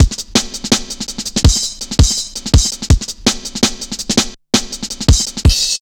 Index of /90_sSampleCDs/Zero-G - Total Drum Bass/Drumloops - 3/track 44 (165bpm)